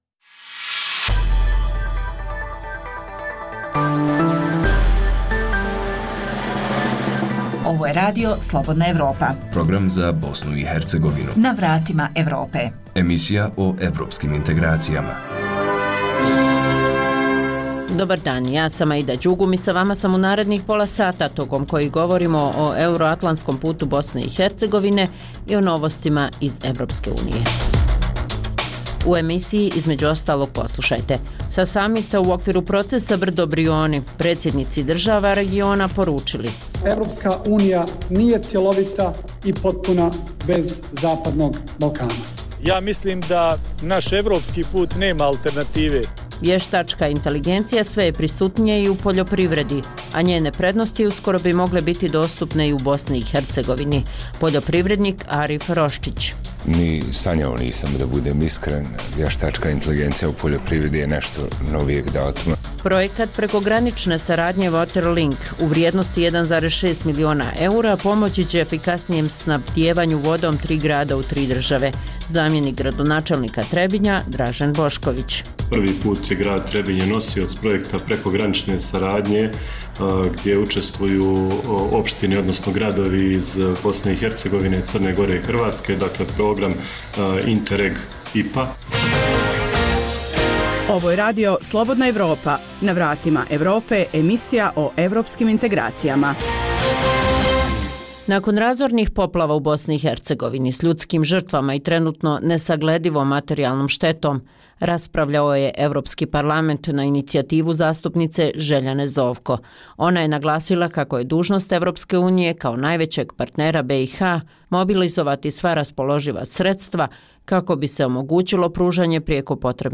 Emisija o putu BiH ka Evropskoj uniji i NATO sadrži vijesti, analize, reportaže i druge sadržaje o procesu integracije.